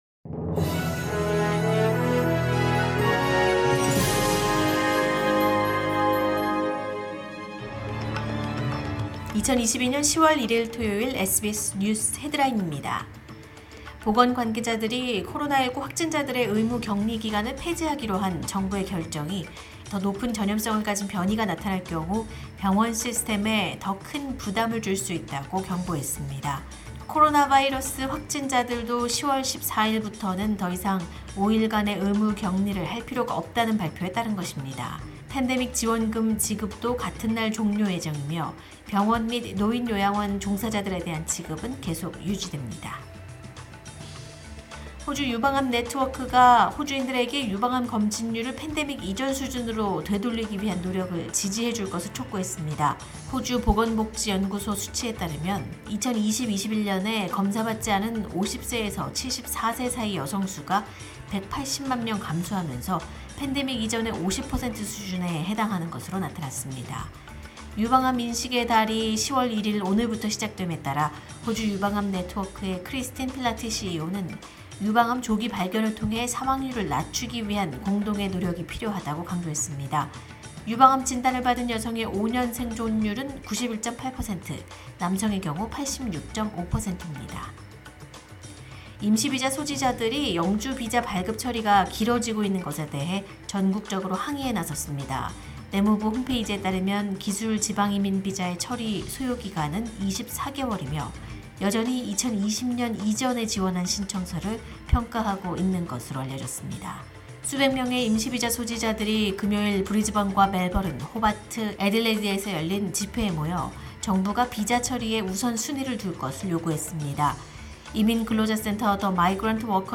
2022년 10월 1일 토요일 SBS 한국어 간추린 주요 뉴스입니다.